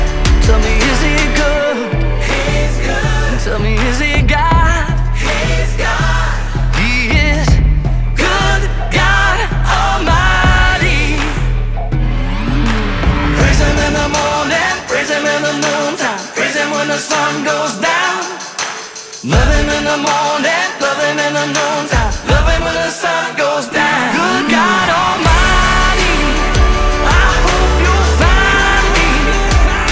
• Christian